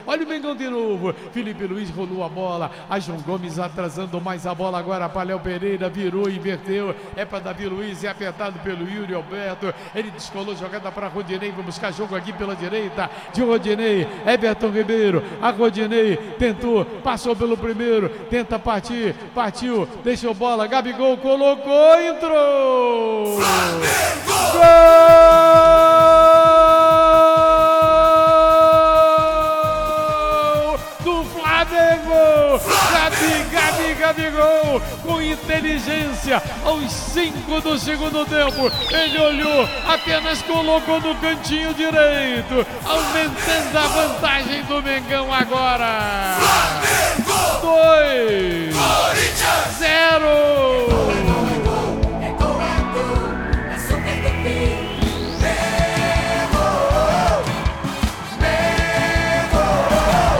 Ouça os gols da vitória do Flamengo sobre o Corinthians na Libertadores com a narração do Garotinho